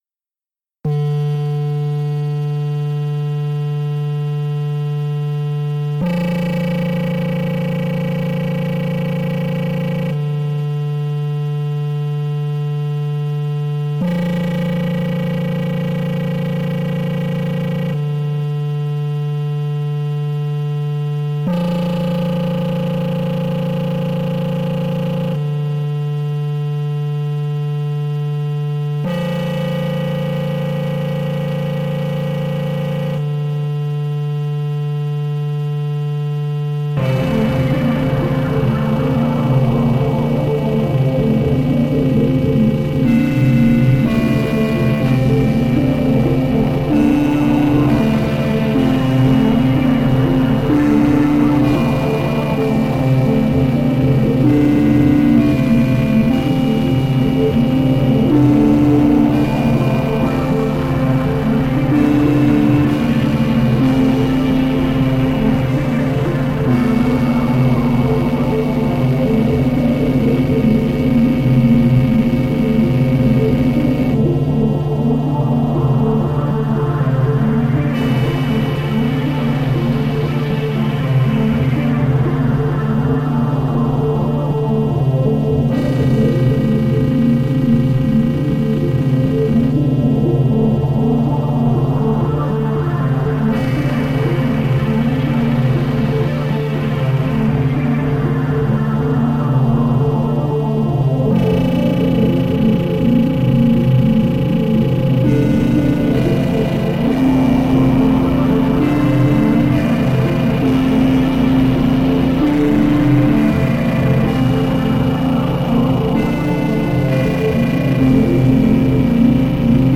Genre: Noise.